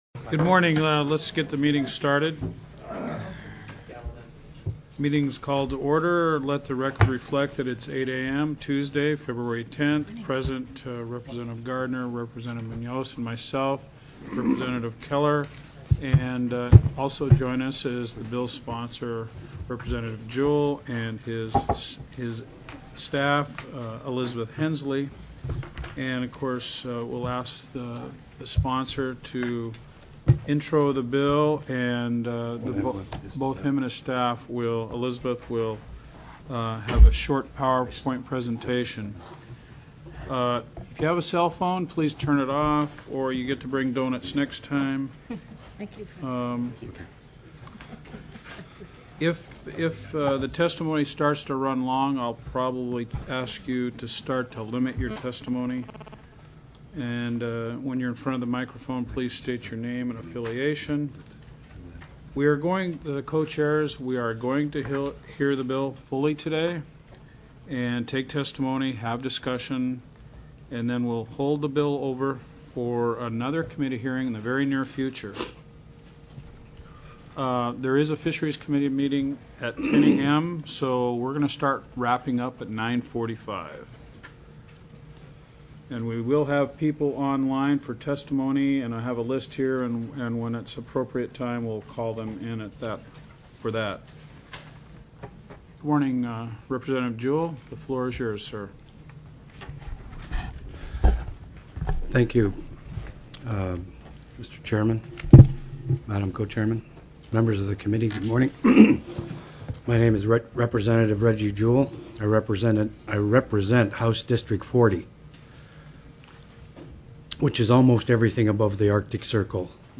02/10/2009 08:00 AM House COMMUNITY & REGIONAL AFFAIRS